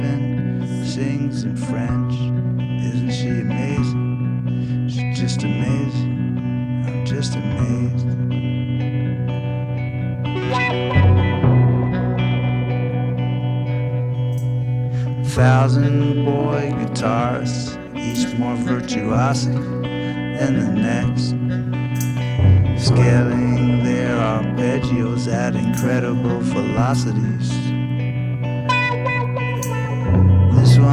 Le guitariste